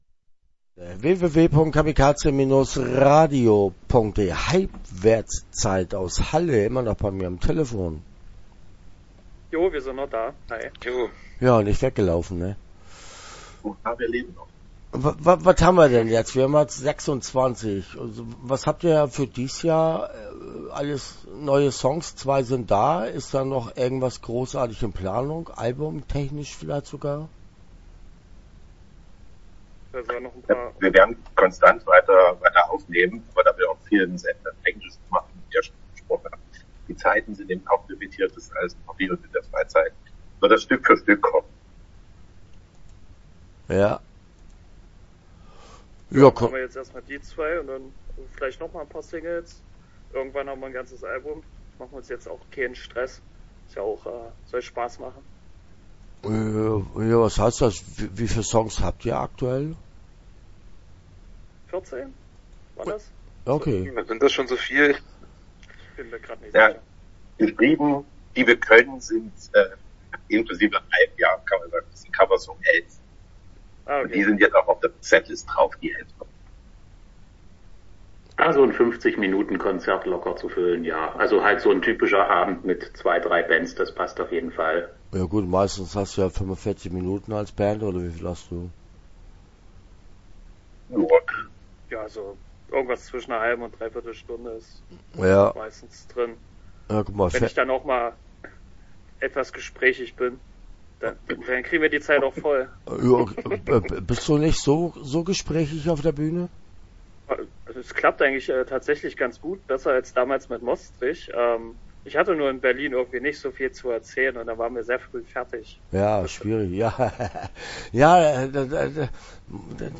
Hypewertszeit - Interview Teil 1 (9:37)